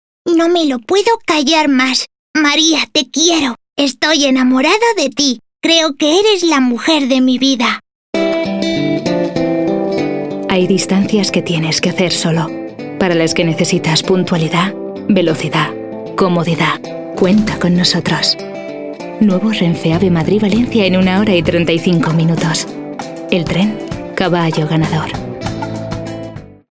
Voz media cálida con registros de tonos altos y timbrada para locuciones y narraciones.
Soprano.
kastilisch
Sprechprobe: Werbung (Muttersprache):